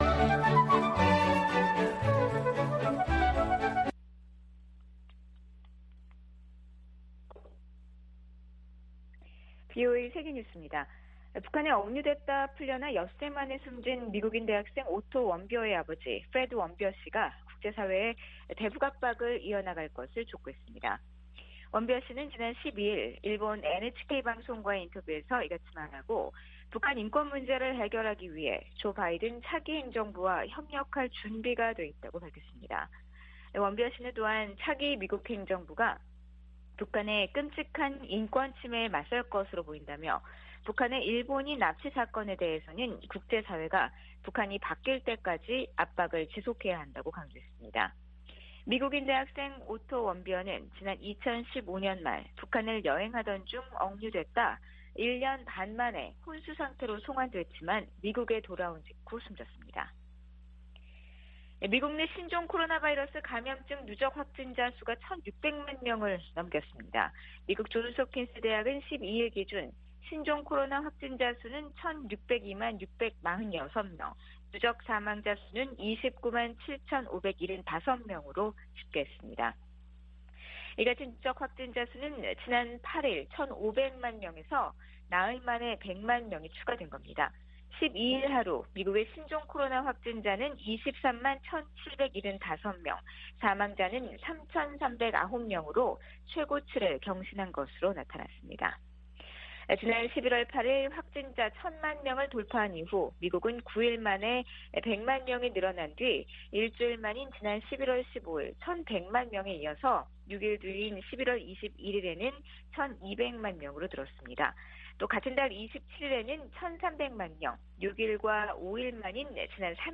VOA 한국어 방송의 일요일 오후 프로그램 2부입니다.